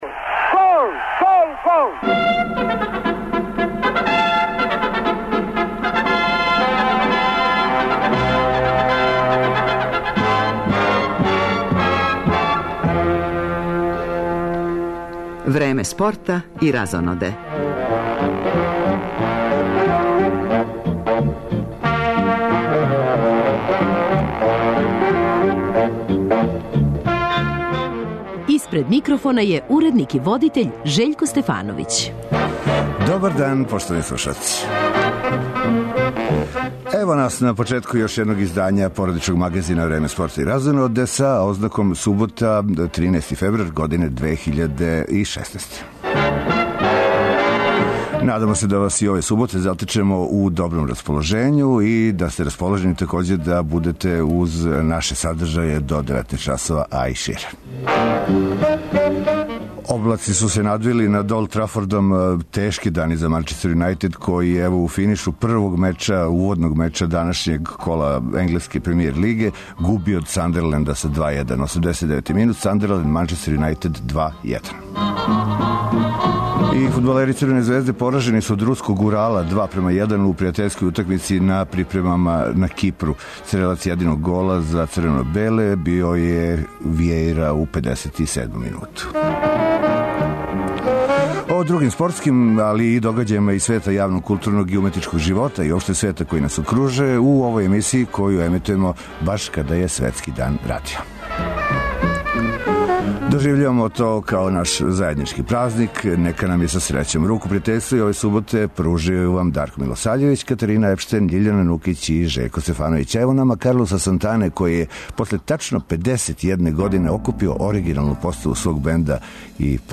разговор
Ових дана навршава се 32 године од Зимских олимпијских игара у Сарајеву - поред звучних записа